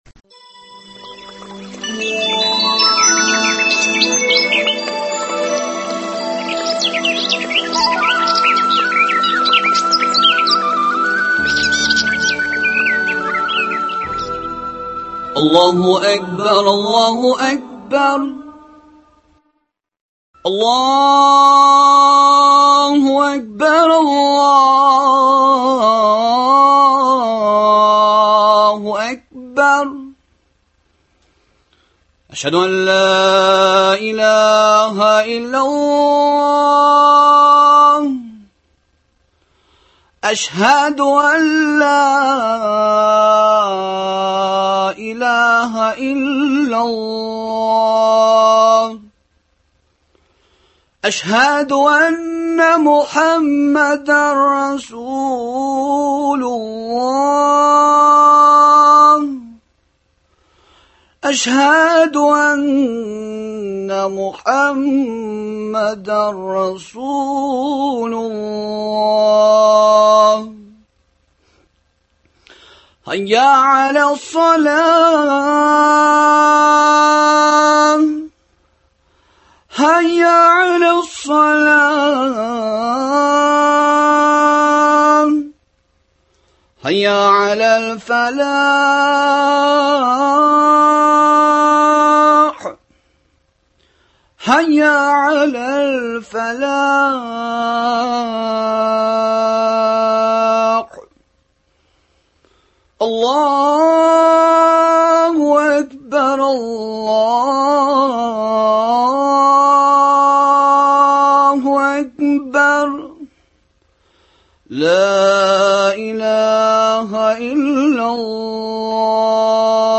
оештырылган әңгәмәләр циклы